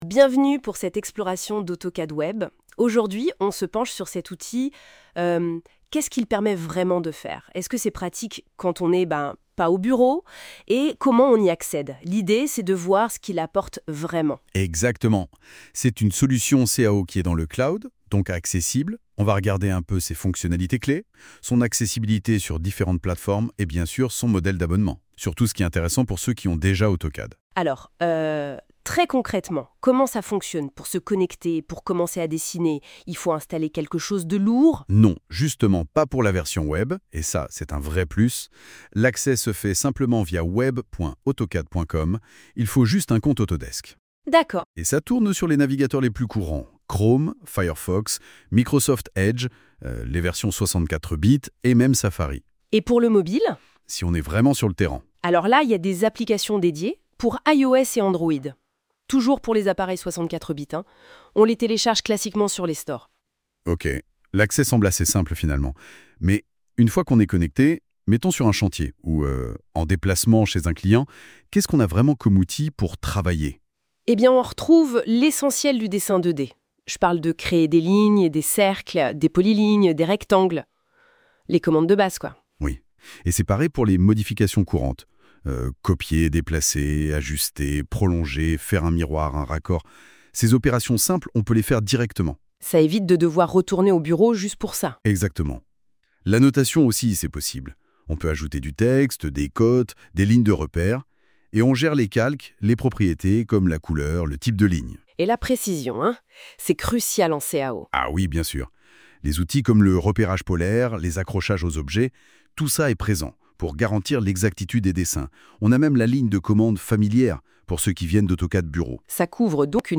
[!Success]Ecoutez la conversation !